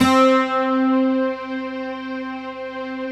Index of /90_sSampleCDs/Optical Media International - Sonic Images Library/SI1_BrightGtrStr/SI1_Shamisen